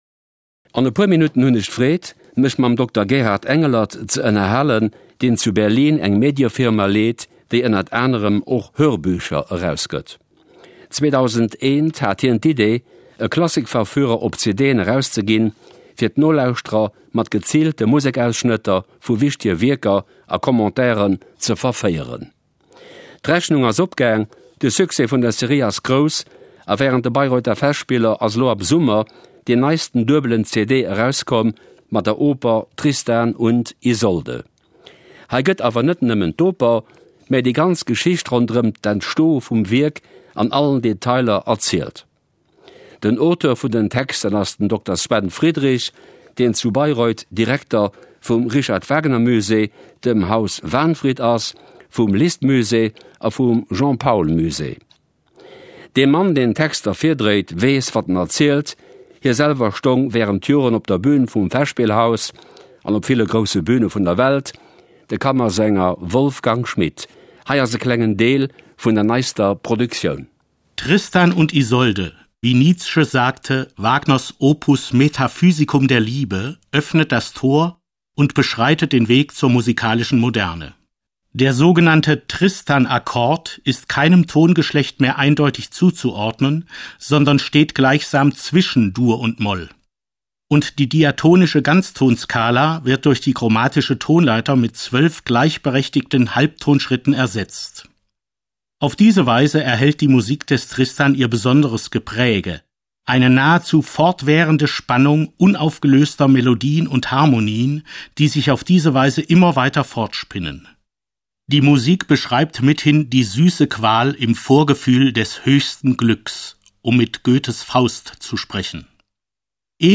Kulturradio_Luxemburg.mp3